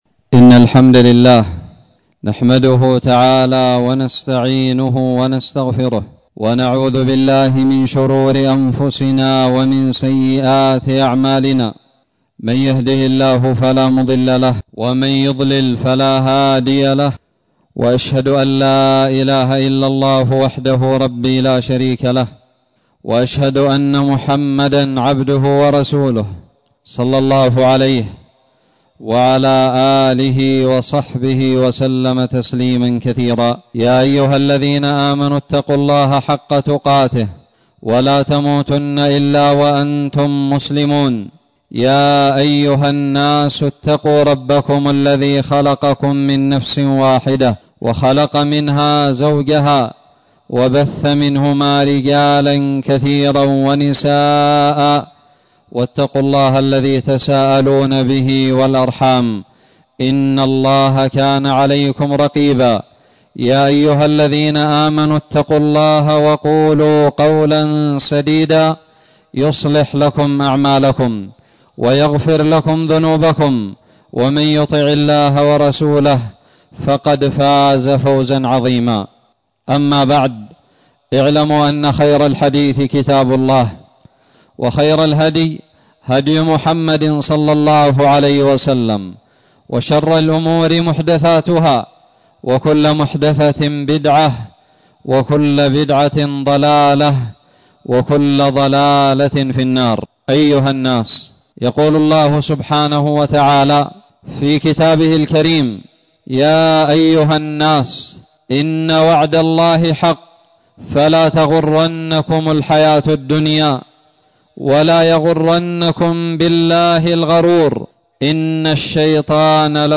خطب الجمعة
ألقيت بدار الحديث السلفية للعلوم الشرعية بالضالع في 28 محرم 1441هــ